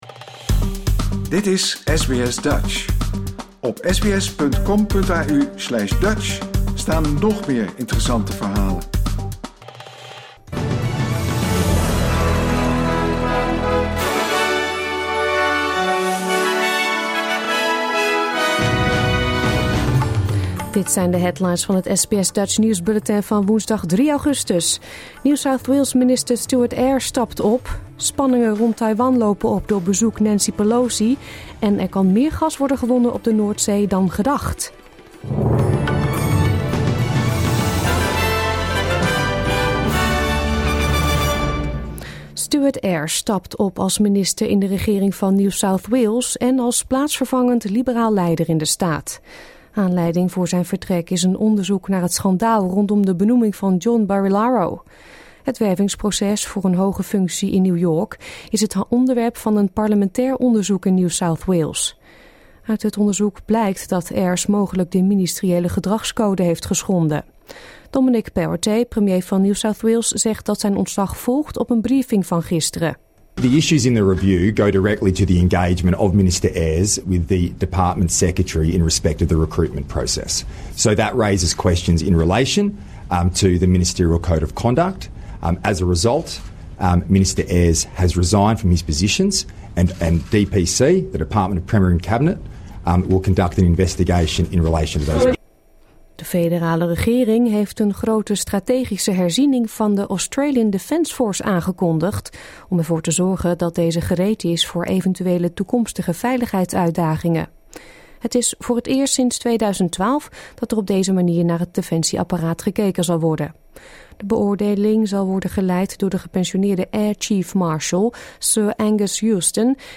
Nederlands / Australisch SBS Dutch nieuwsbulletin van woensdag 3 augustus 2022